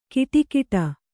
♪ kiṭikiṭa